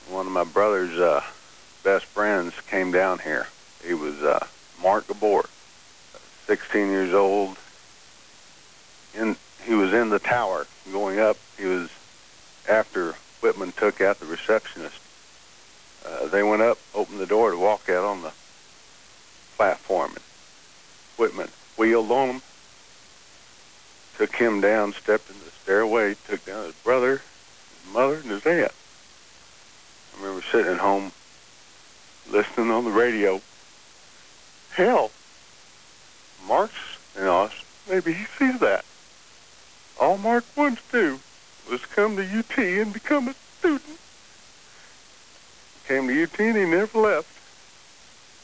Audio clip courtesy of KLBJ-AM radio, August 1, 1996.